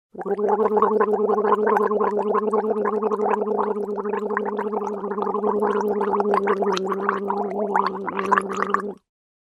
Звук полоскания горла женщиной